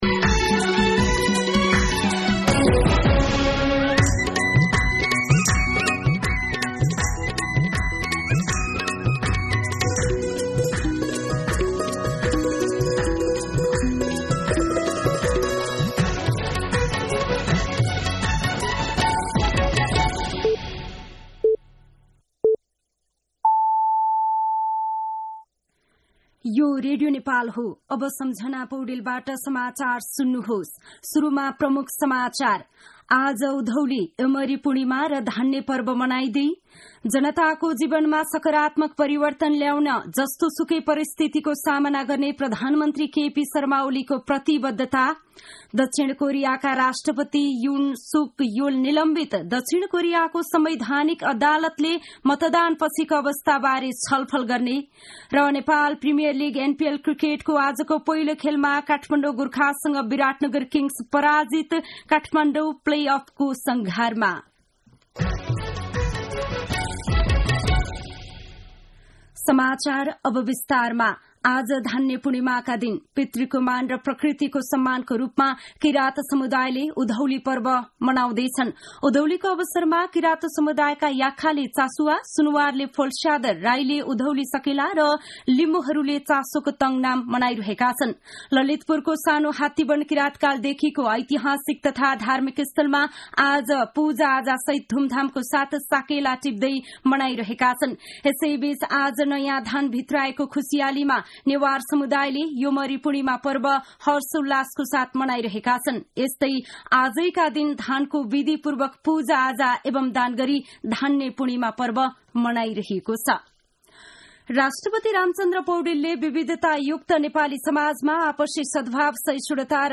दिउँसो ३ बजेको नेपाली समाचार : १ पुष , २०८१